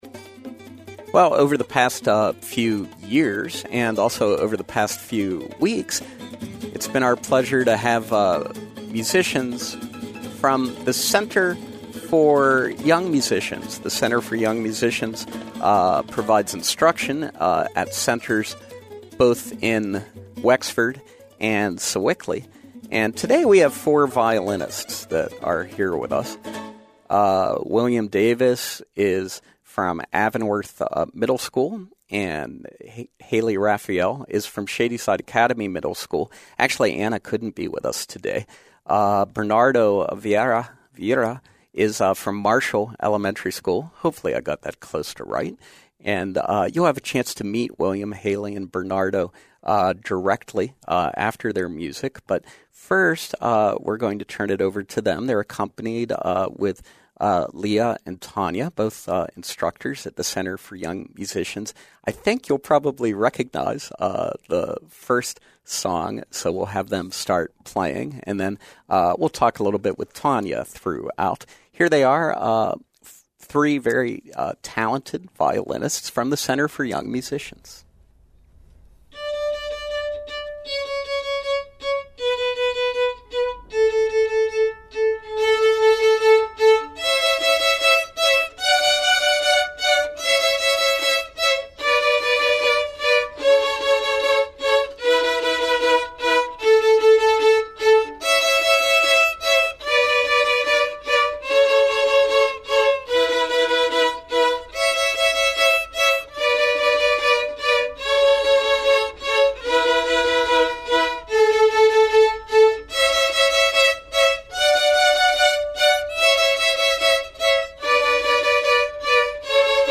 This week’s installment of our Youth Music Series with the Center for Young Musicians features young and talented CYM violinists, live on SLB.